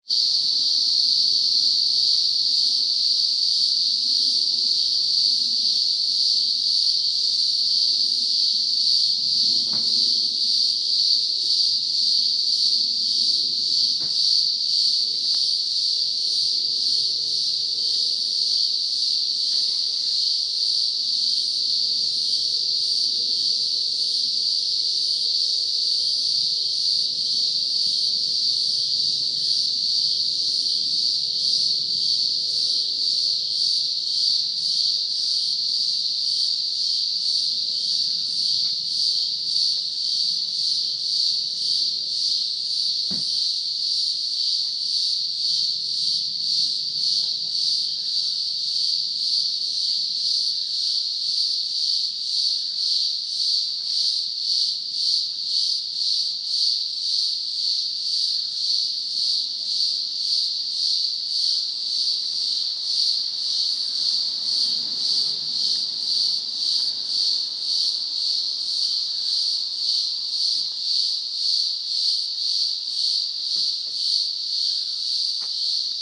Cicada